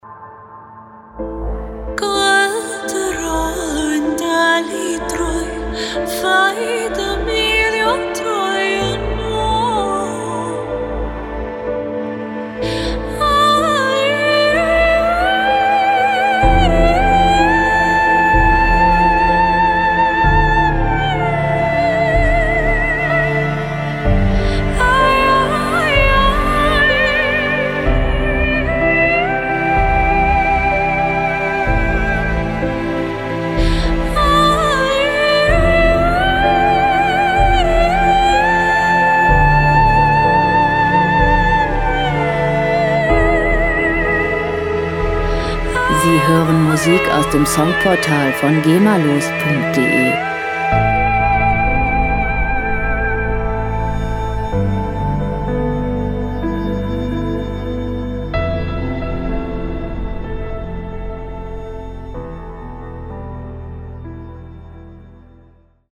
• New Age